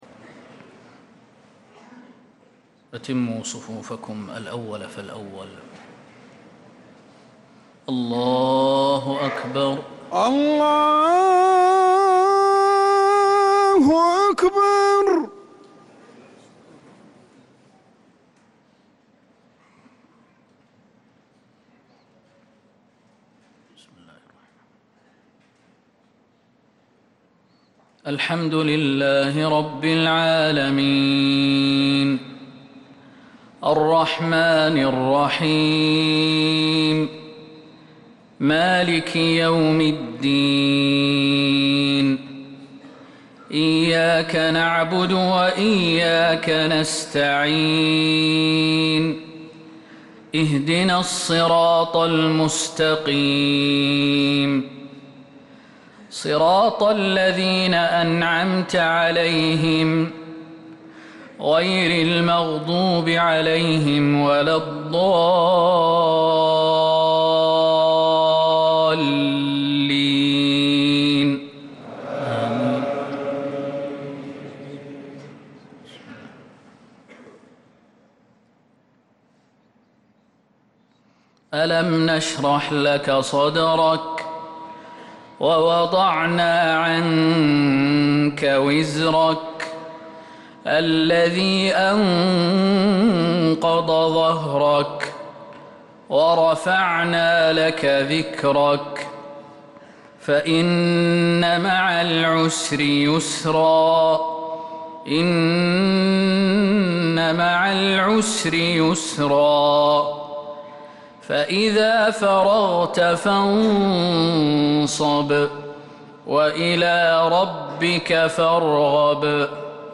مغرب الجمعة 9 محرم 1447هـ سورتي الشرح و النصر كاملة | Maghrib prayer from Surah ash-Sharh and an-Nasr 4-7-2025 > 1447 🕌 > الفروض - تلاوات الحرمين